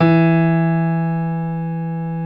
55p-pno16-E2.wav